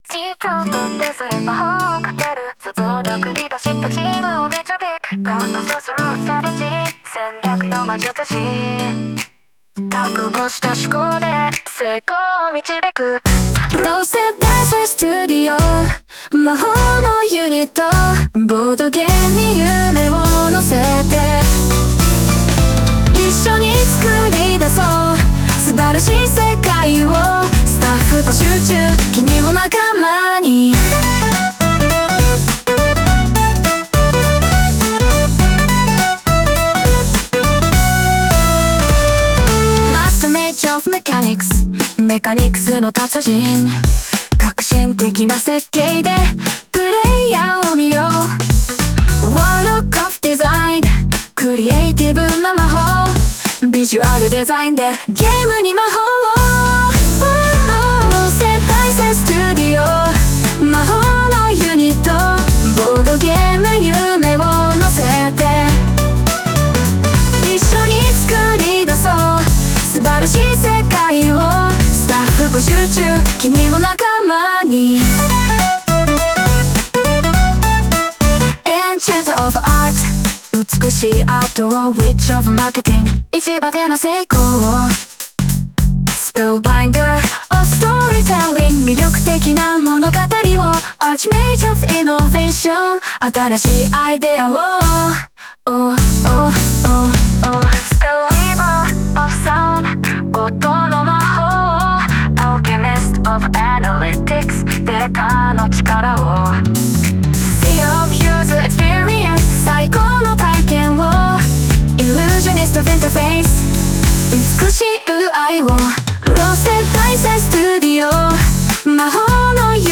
柔らかく感情豊かな声でリスナーを魅了するアーティスト。